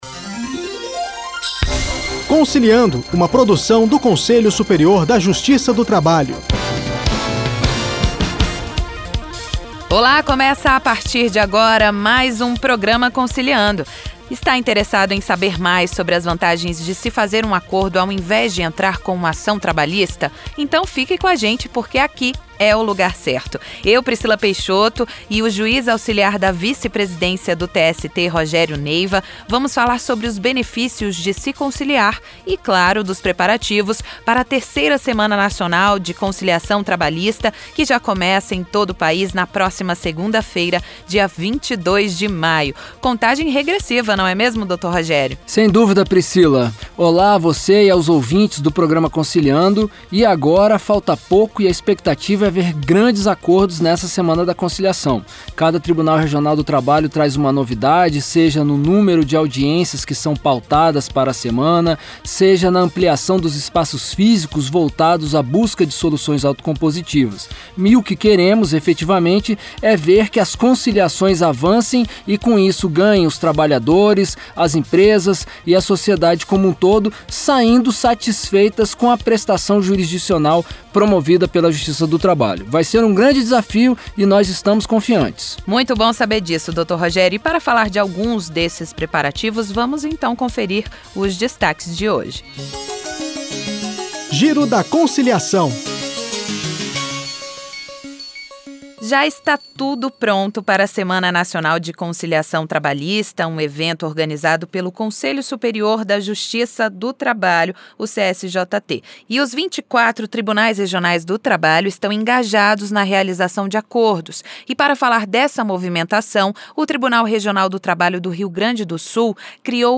O juiz do trabalho Rogério Neiva explica no quadro “Dica do dia”.
Na entrevista, a juíza conta como estão os preparativos para a Semana da Conciliação no TRT da 10ª Região.